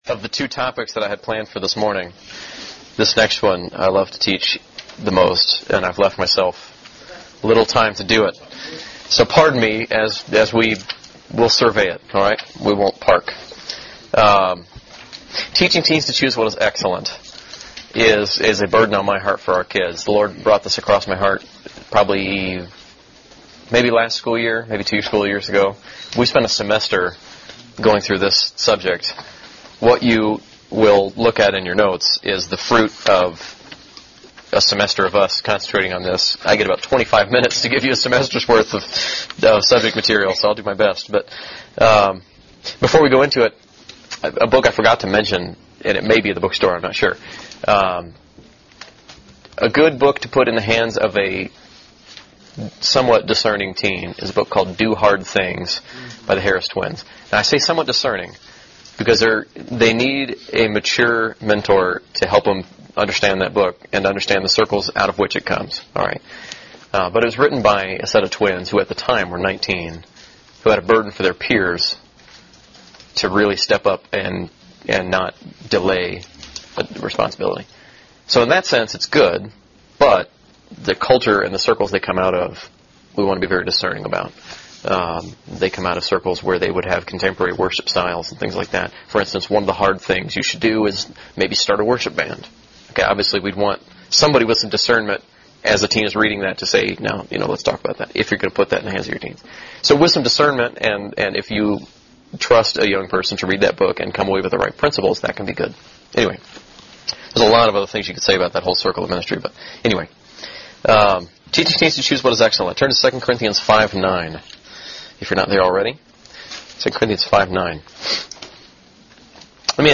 Workshop